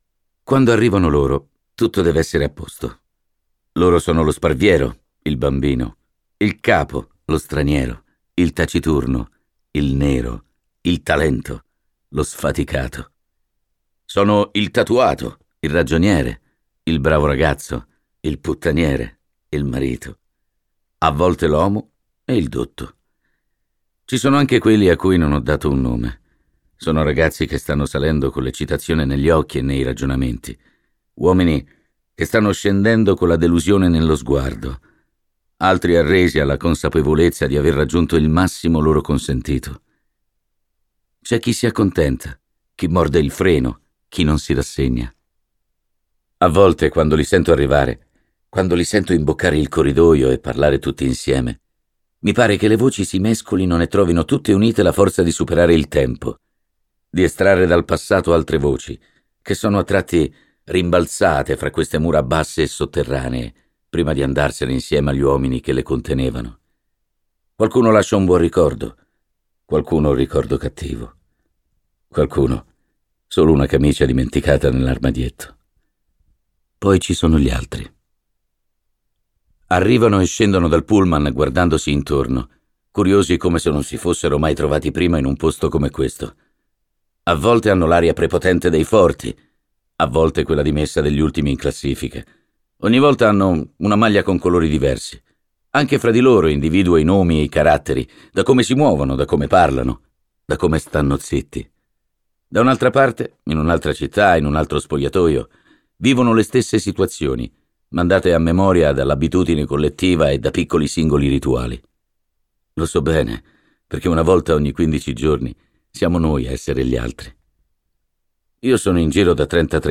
letto da Pino Insegno
Versione audiolibro integrale